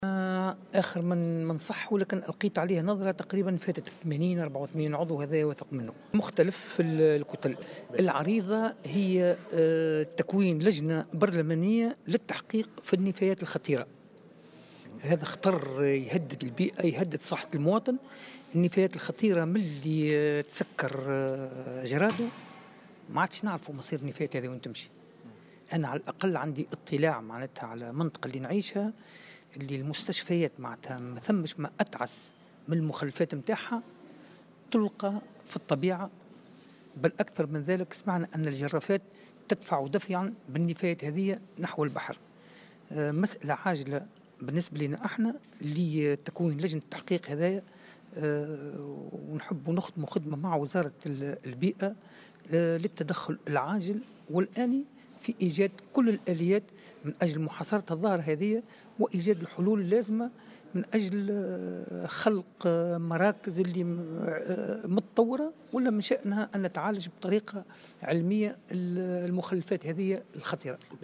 وقال العيادي في تصريح لمراسل "الجوهرة أف أم" اليوم إنه لم يعد يُعرف مصير النفايات الخطيرة منذ غلق مصب جرادو، وهو ما يطرح عديد الأسئلة، وفق تعبيره.